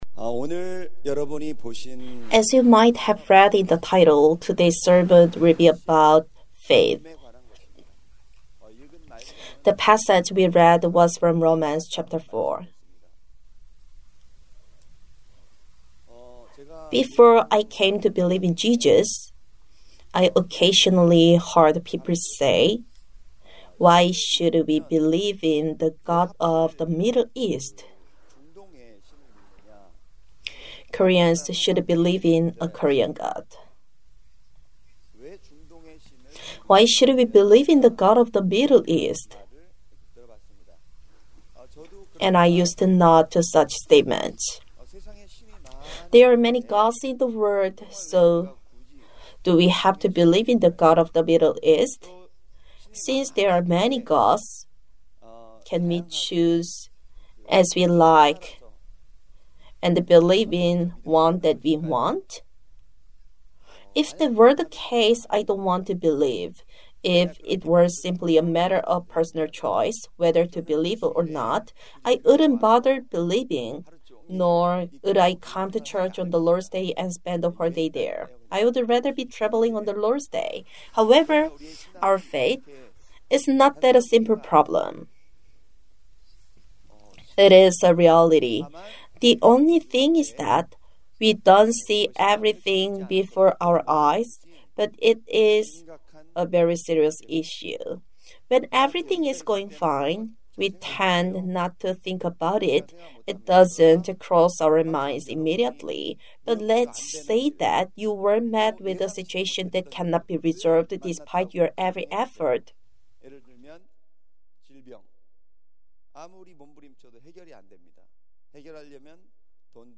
As you might have read in the title, today’s sermon will be about faith.